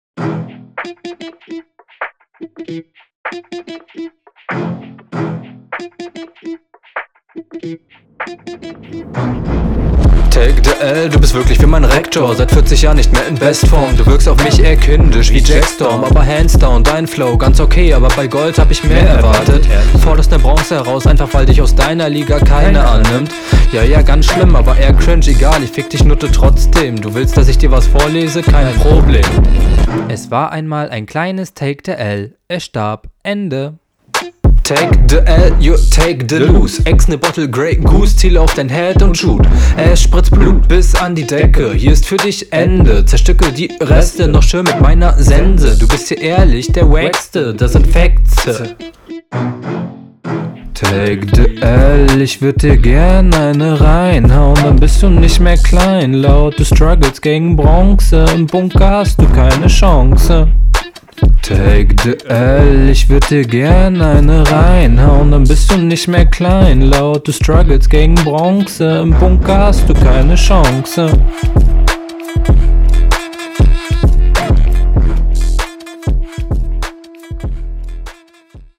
Einstieg überraschen gut, erste Zeile war auf dem Takt, dann zu viele Wörter versucht in …